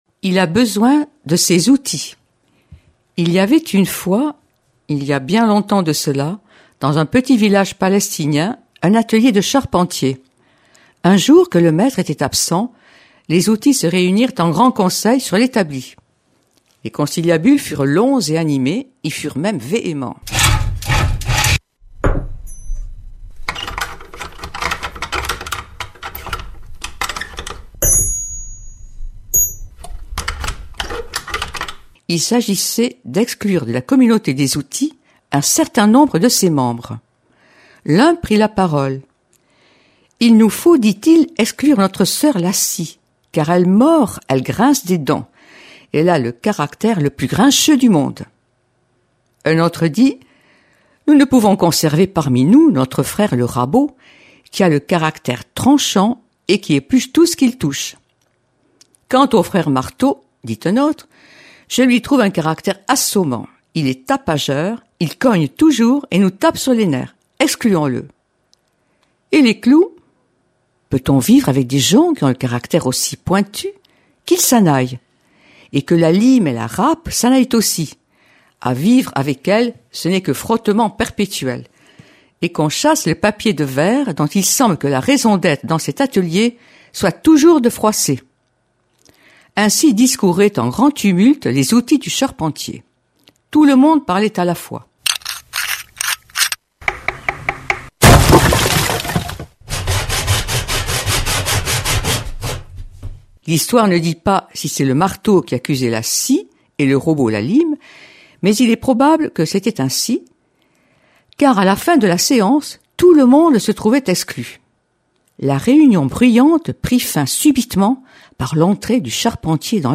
| Contes de Noël